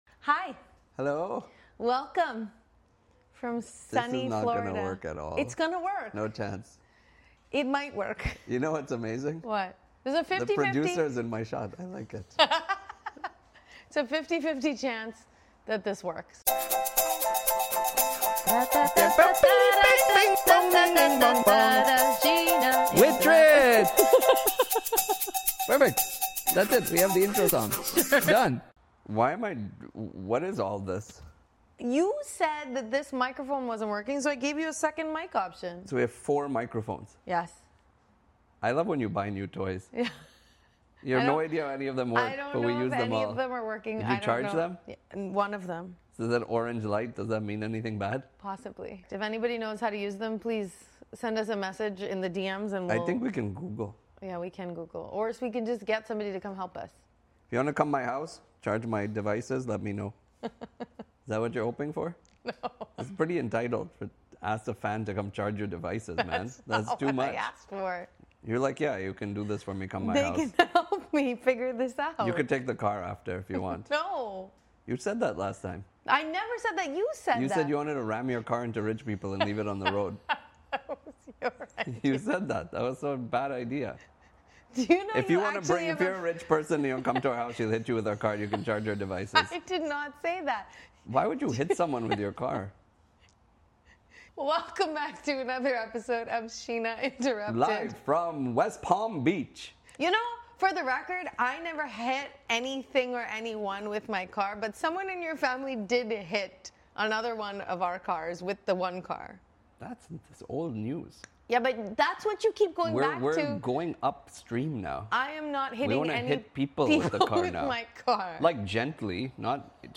This week we brought the podcast to paradise and attempted to record a “peaceful” vacation episode… let’s just say the outcome is quite the opposite. This episode includes: too many mics, no idea how to use them, and more chaos than normal.